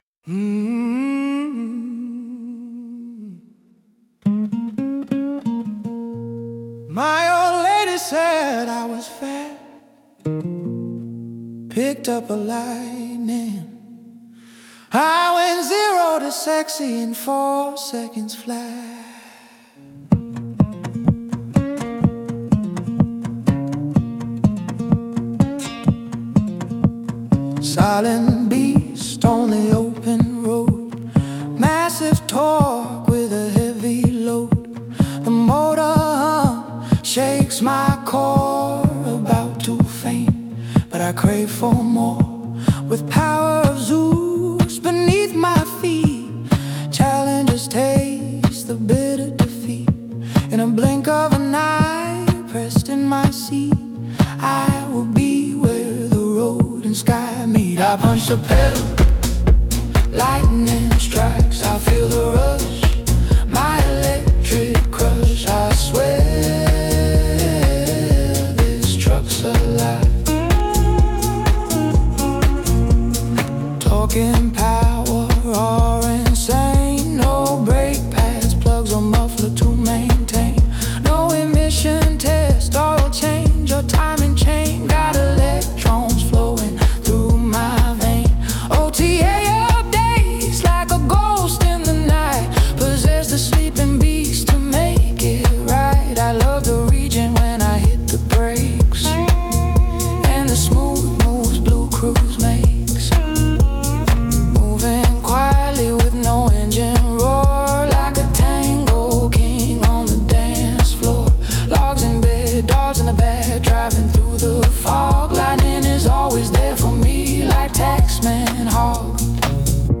The House Version:
Alive_House.mp3